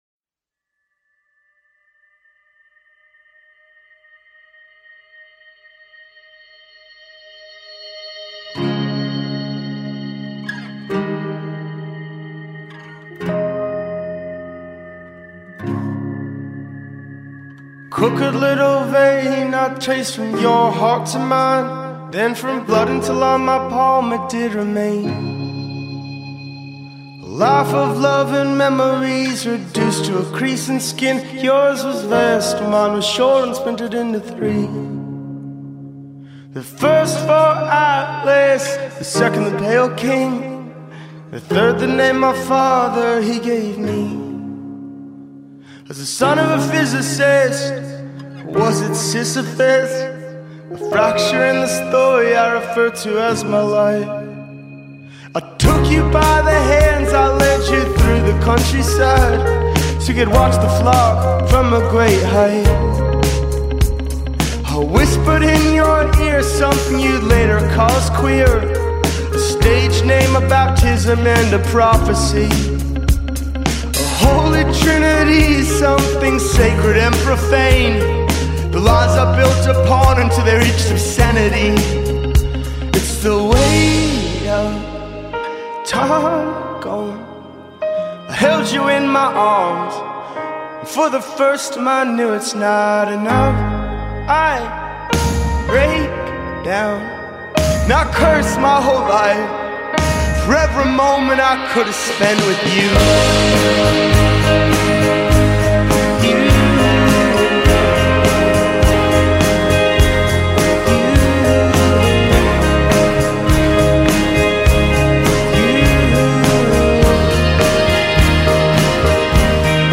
rock band